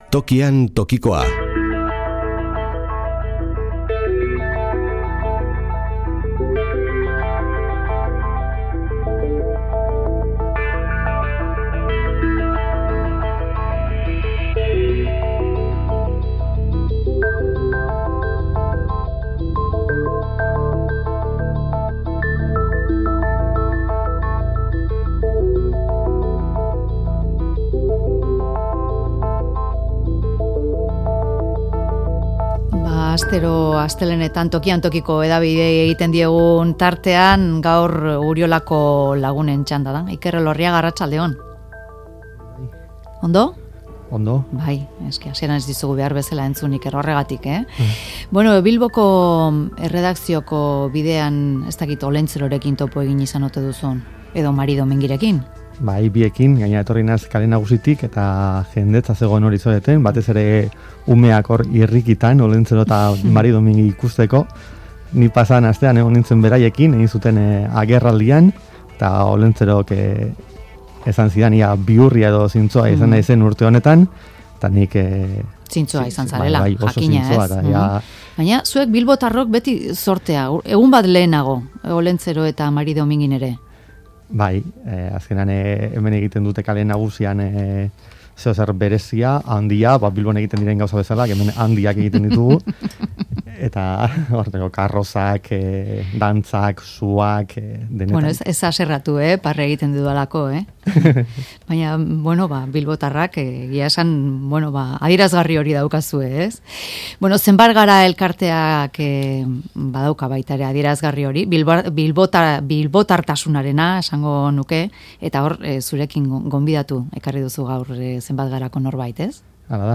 elkarrizketatu dugu Mezularian